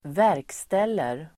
Uttal: [²v'är:kstel:er]